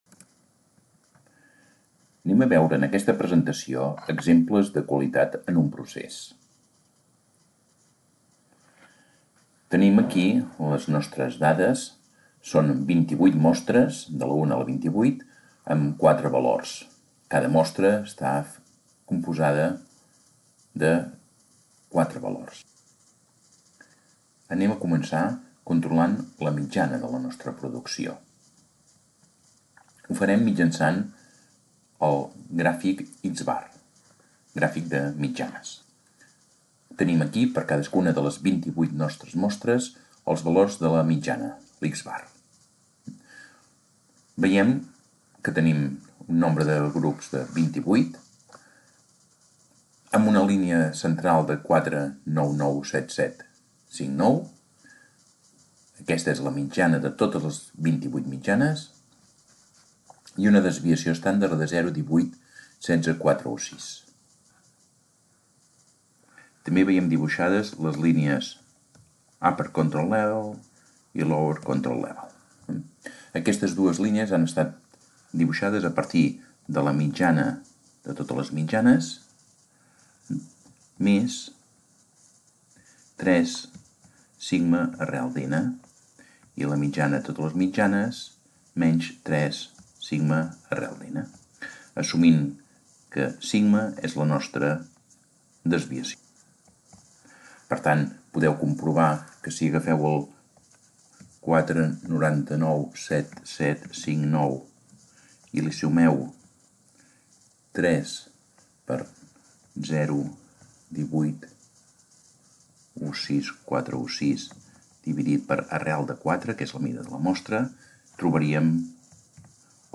Lesson of the subject of Theory of statistics on quality in a process through examples